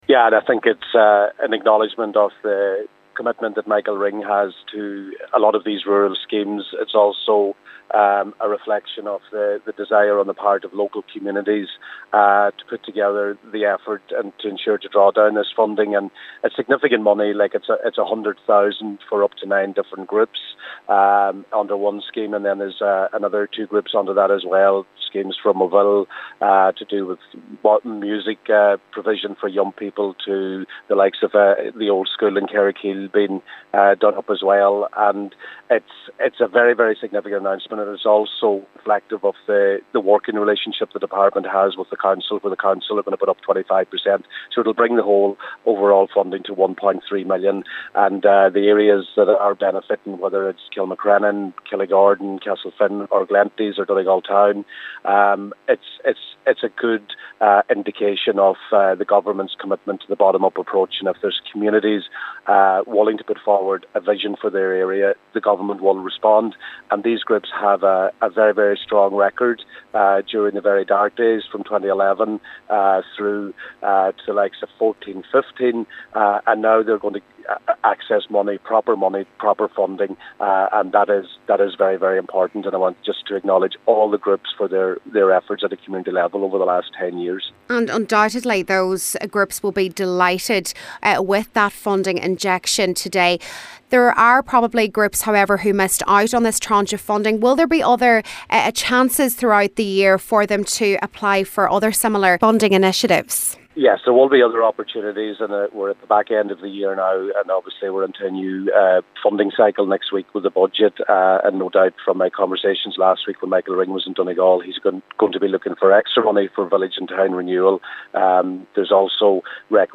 Donegal Minister Joe McHugh: